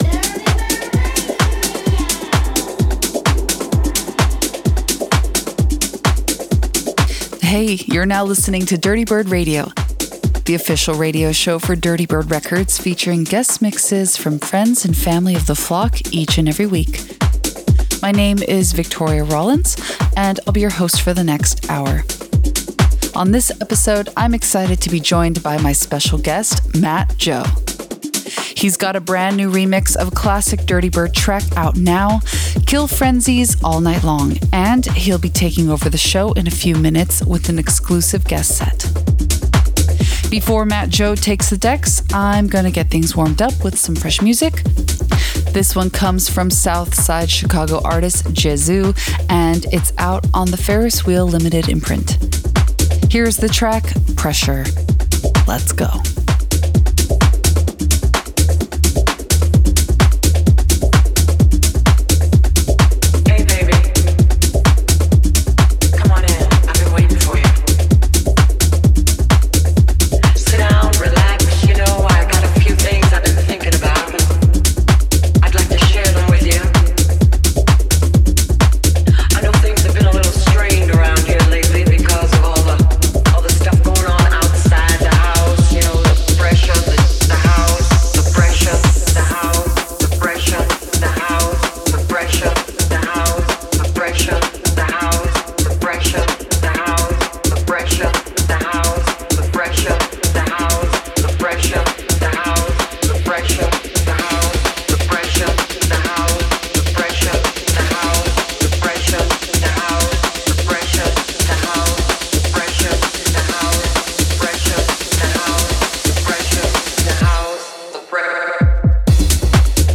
exclusive booty-shaking set